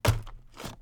wheat_punch_2.wav